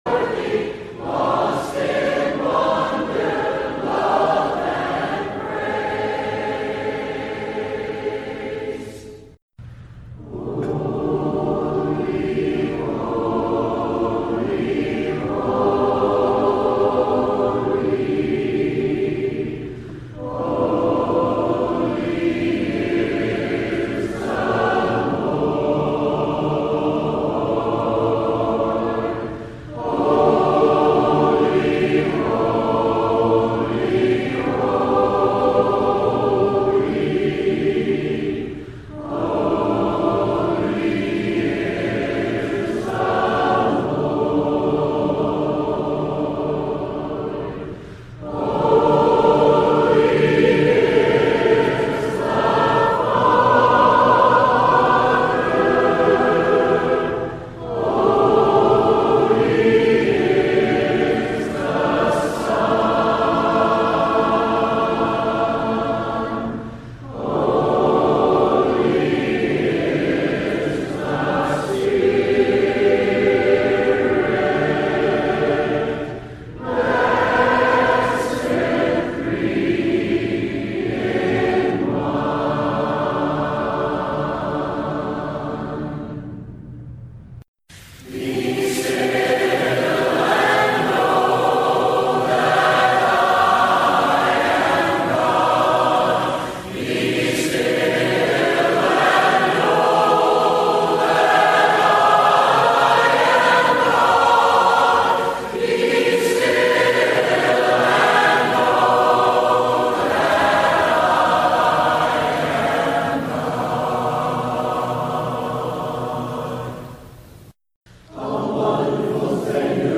Program Info: Live program from the Nesbit church of Christ in Nesbit, MS.